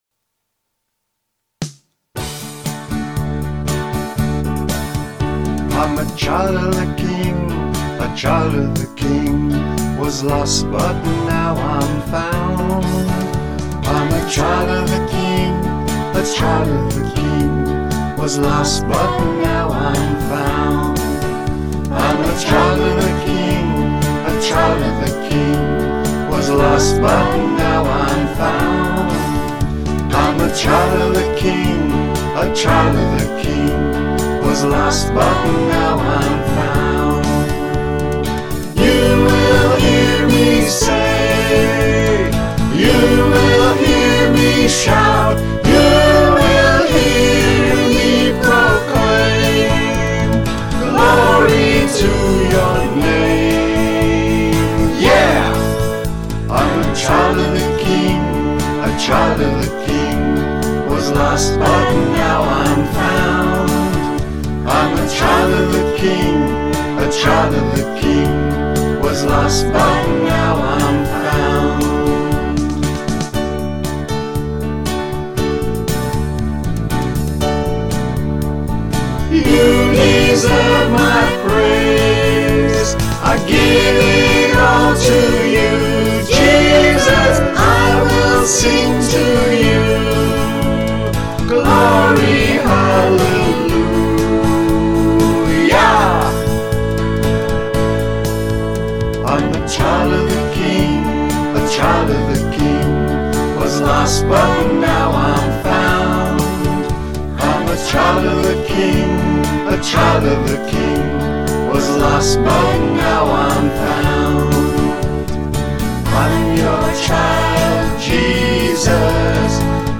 A Family Worship Song